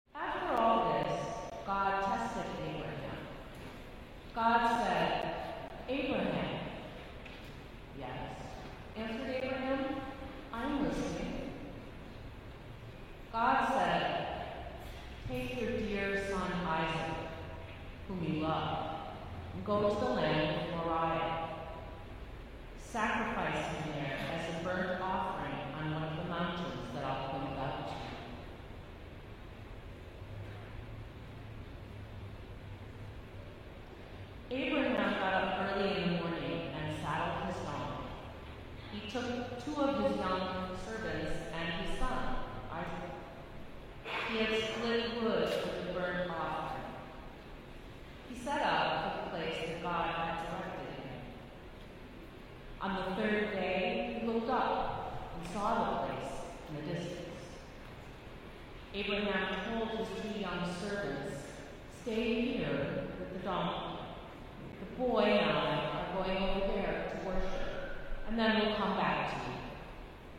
About UsSermons